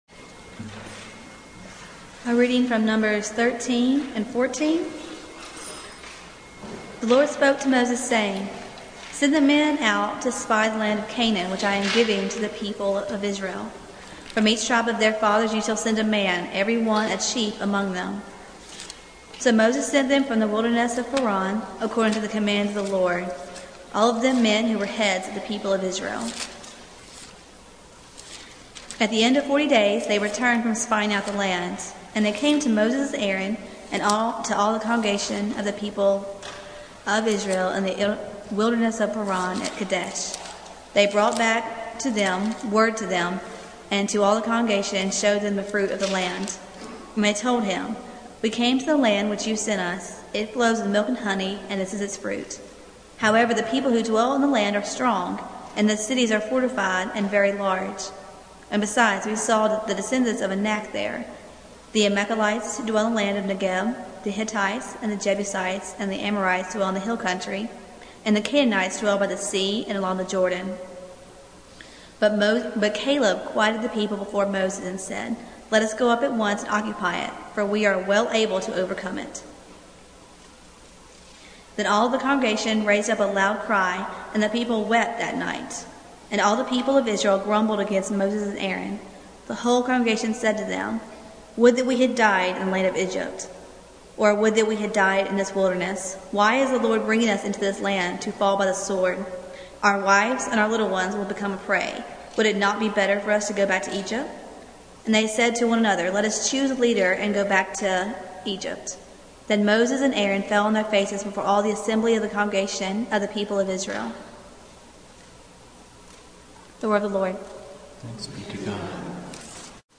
Series: Roadblocks in the Wilderness Service Type: Sunday Morning